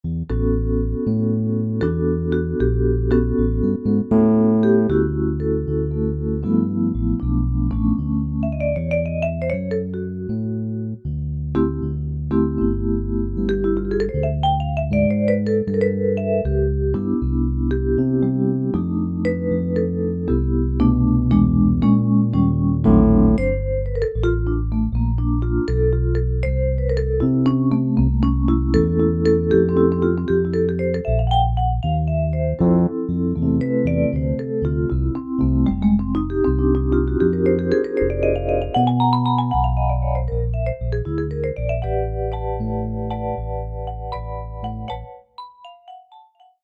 Звучание роялей Shigeru Kawai SK-EX и Kawai EX, воссозданное с помощью технологии формирования звука Harmonic Imaging